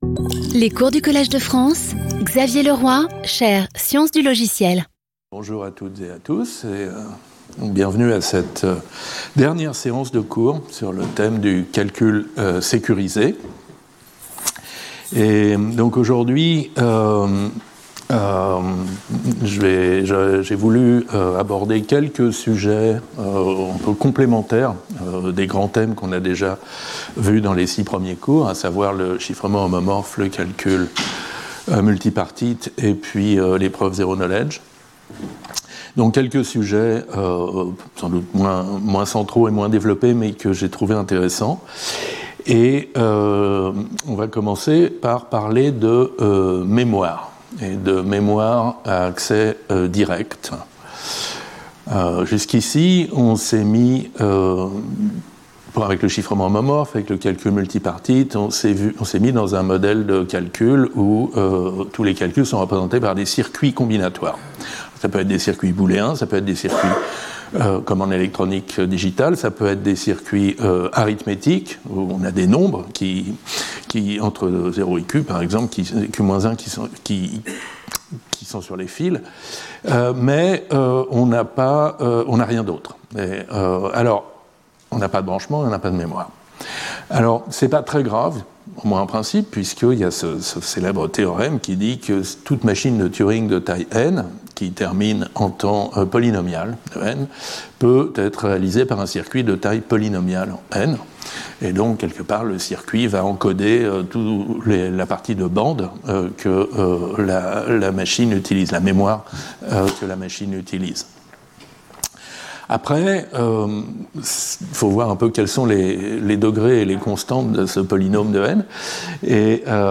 Speaker(s) Xavier Leroy Professor at the Collège de France